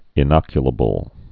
(ĭ-nŏkyə-lə-bəl)